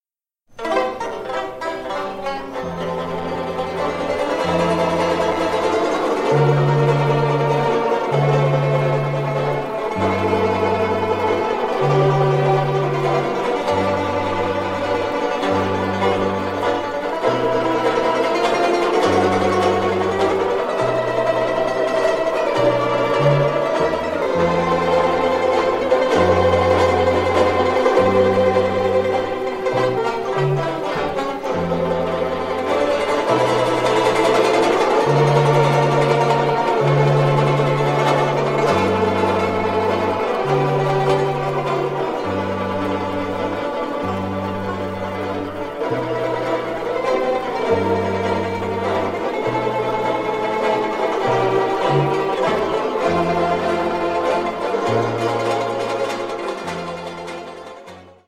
12-beat intro.
This song is in 3/4 walt time.
Listen to the Southern California Banjo Band perform "Melody Of Love" (mp3)